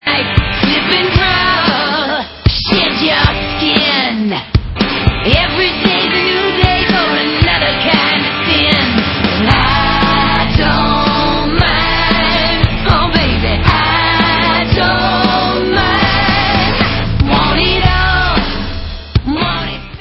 sledovat novinky v oddělení Rock